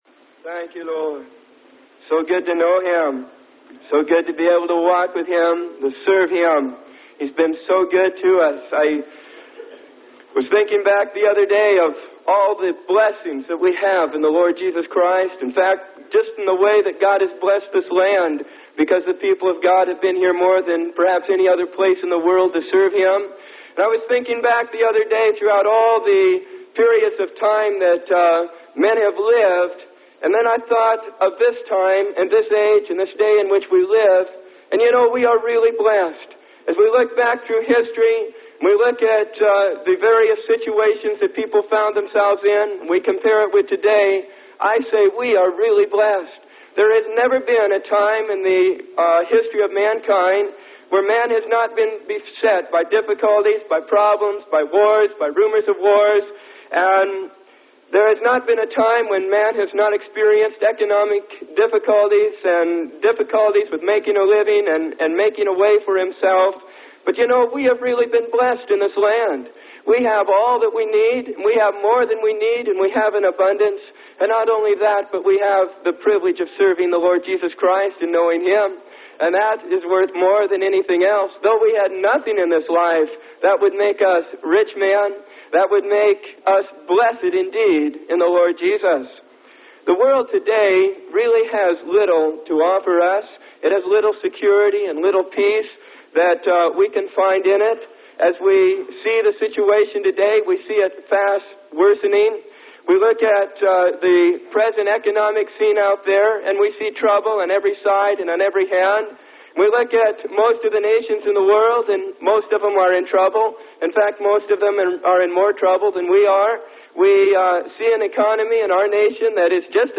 Sermon: Jesus Is All I Need.